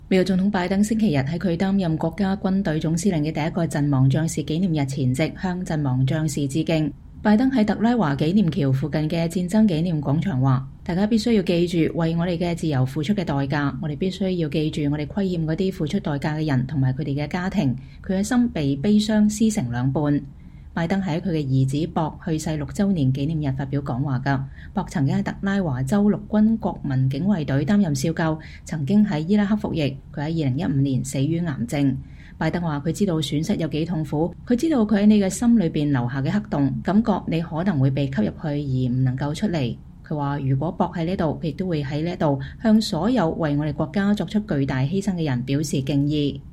拜登在特拉華紀念橋附近的戰爭紀念廣場說：“我們必須記住為我們的自由付出的代價。我們必須記住我們虧欠那些付出代價的人和他們的家庭。我的心被悲傷撕成兩半。”
拜登是在他的兒子博去世六週年紀念日發表講話的。